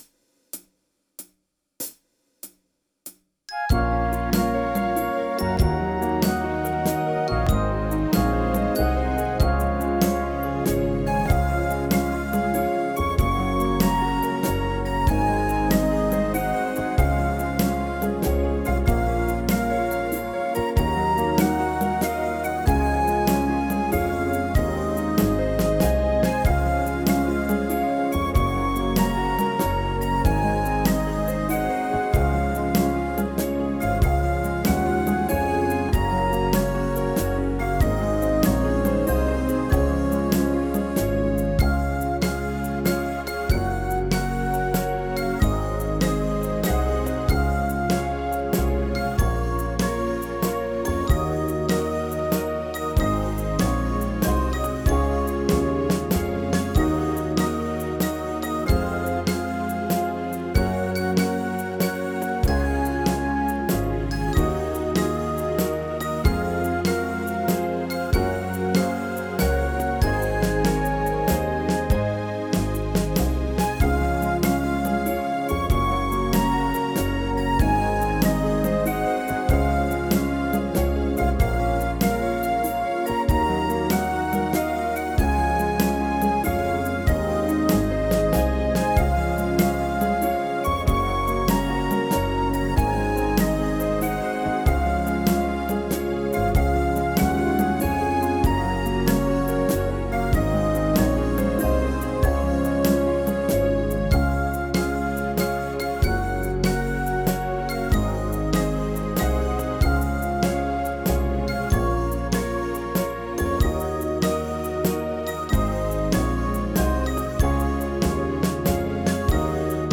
versión instrumental multipista